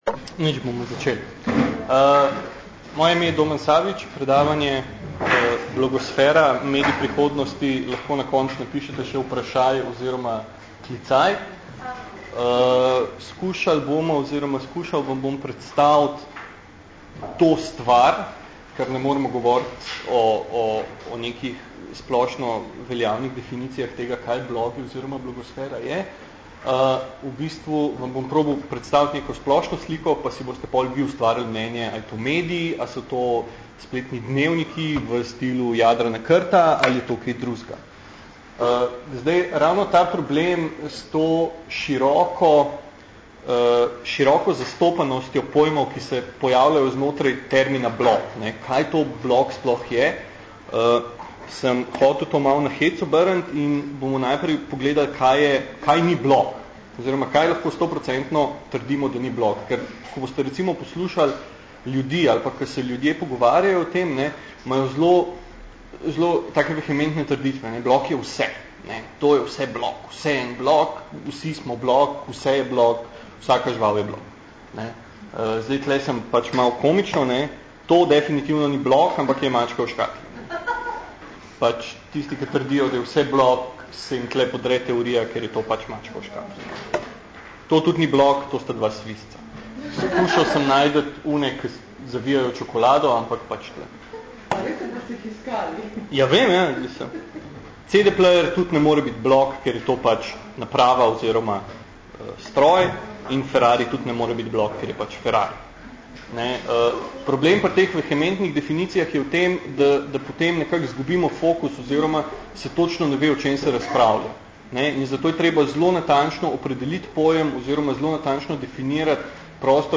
Oh, and this just in…you can now listen to my newest lecture on demystification of the blogosphere.